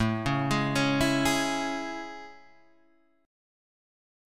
A Major 7th
AM7 chord {5 7 6 6 5 5} chord